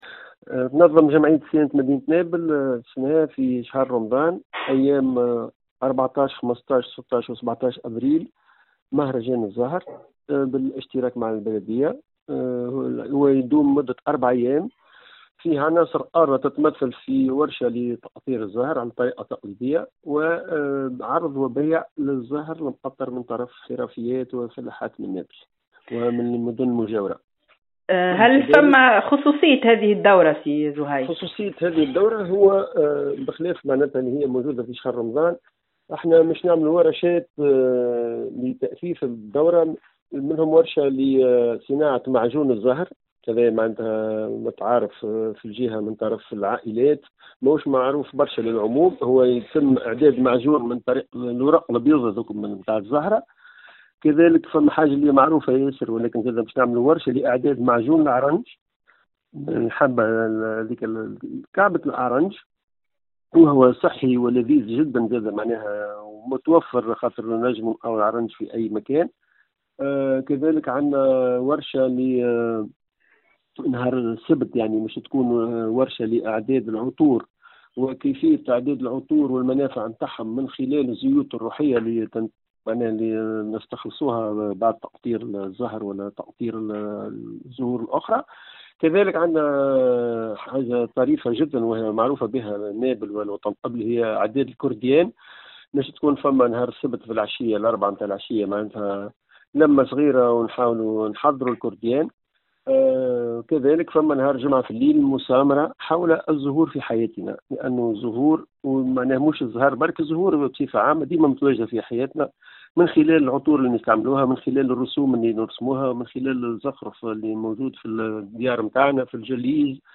فرصة لإحياء الحرفة و المحافظة على الشجرة العريقة (تصريح)